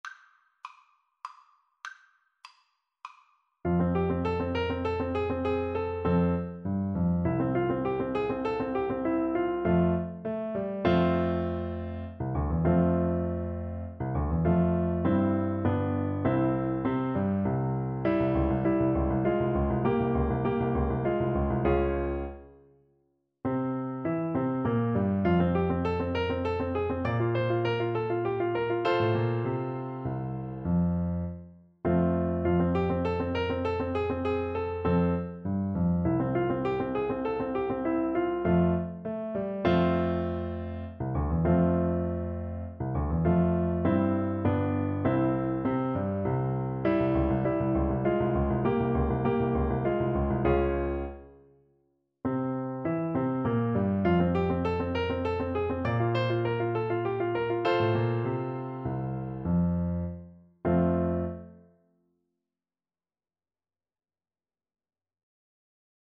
3/4 (View more 3/4 Music)
Classical (View more Classical Clarinet Music)